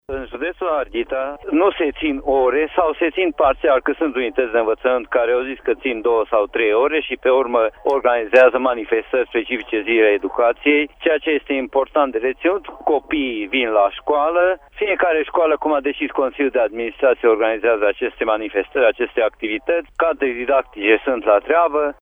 În județul Harghita orarul de mâine va include și activități speciale a explicat, pentru Radio Tîrgu-Mureș, inspectorul școlar general, Petru Ioan Gârbea: